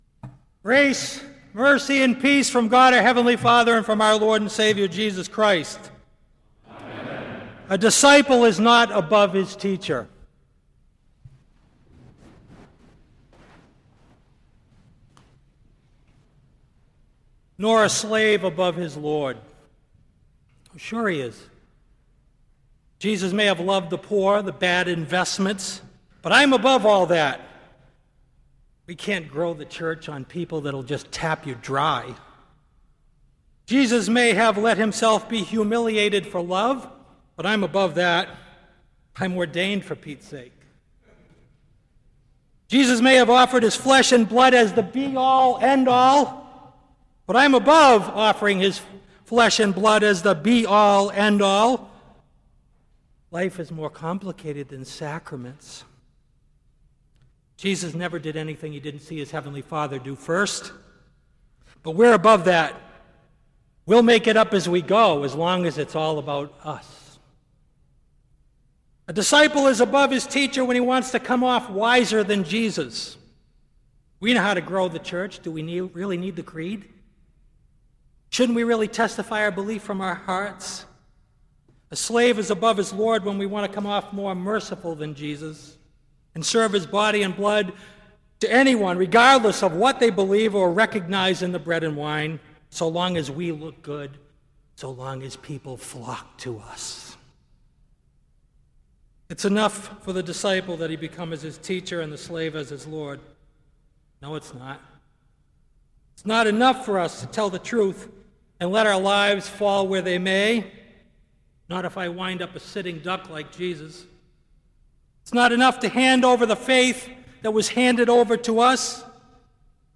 Kramer Chapel Sermon - June 22, 2005